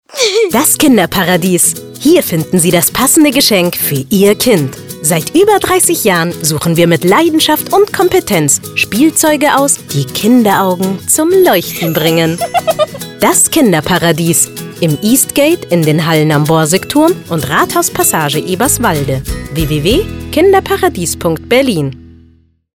Unsere Werbespots auf 98,2 Radio Paradiso